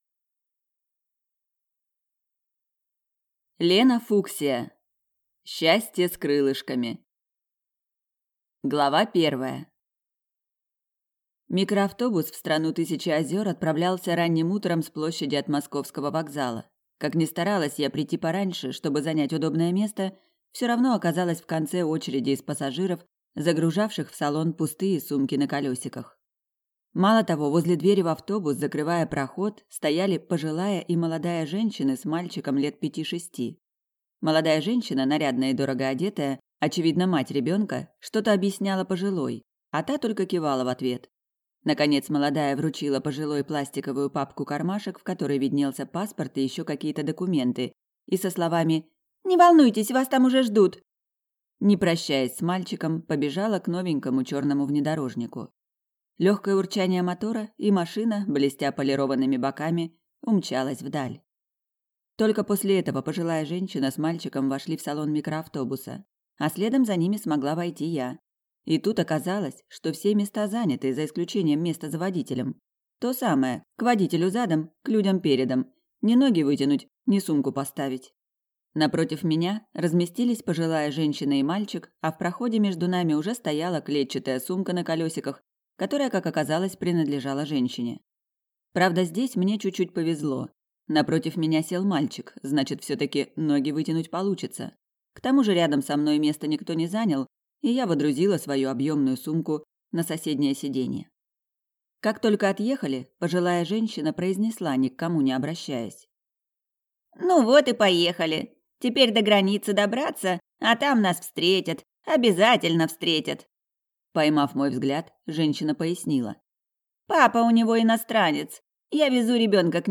Аудиокнига Счастье с крылышками | Библиотека аудиокниг